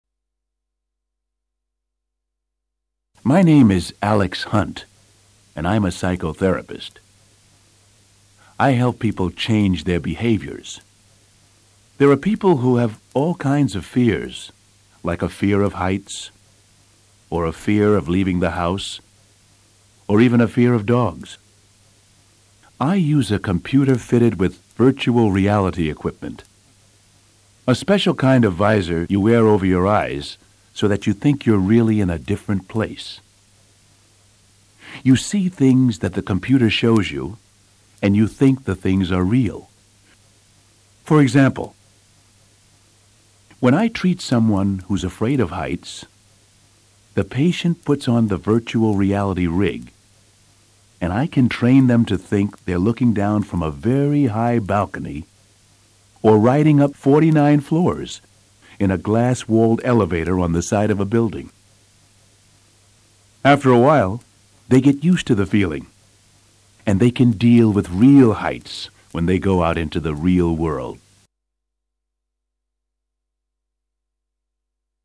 psychotherapist